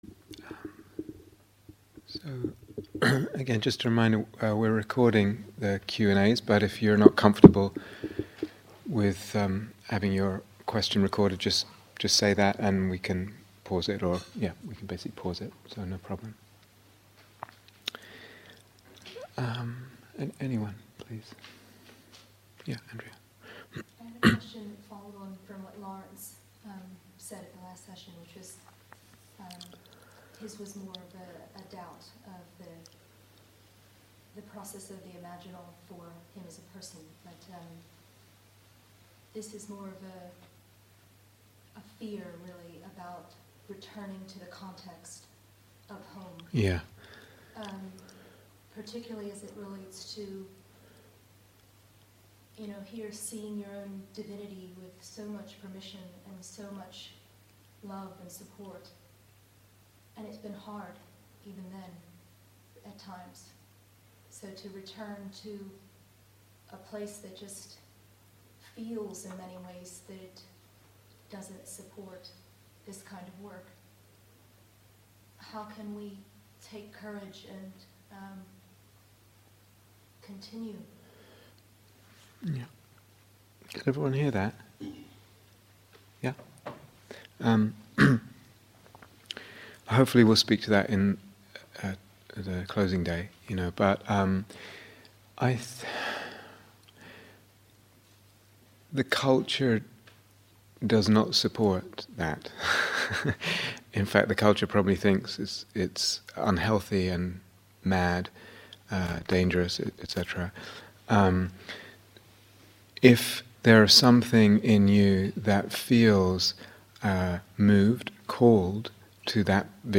The Imaginal and the Middle Way (Q & A)